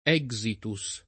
exitus [lat. $g@ itu S ] s. m. — eufemismo dell’uso medico per «morte»